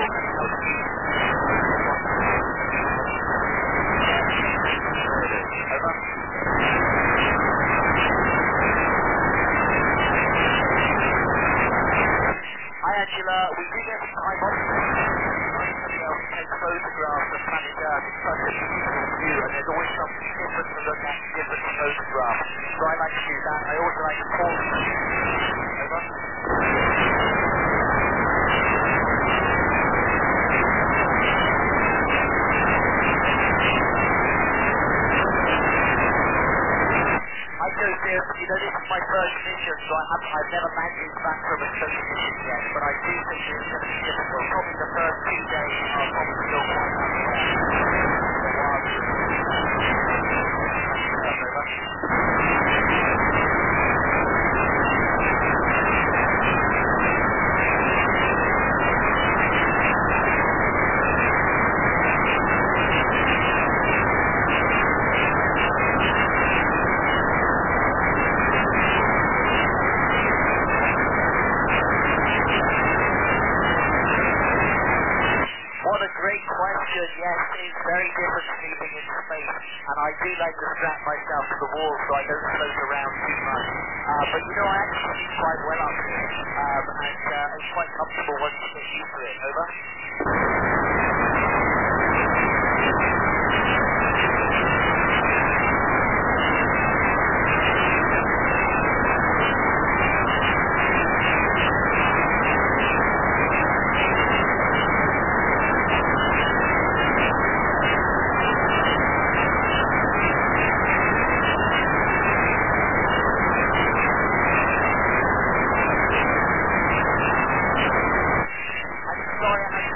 RS0ISS Recording in France